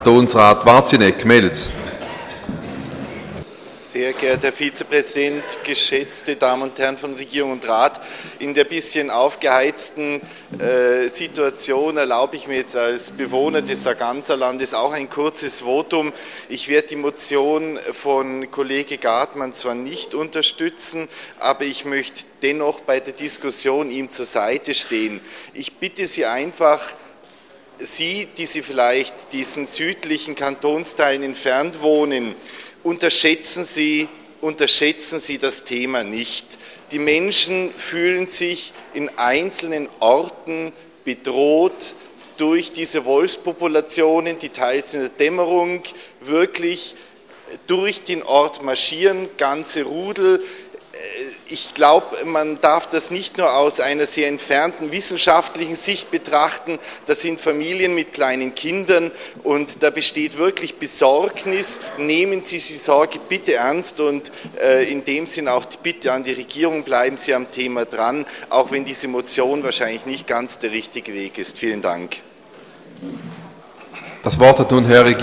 Session des Kantonsrates vom 23. bis 25. Februar 2015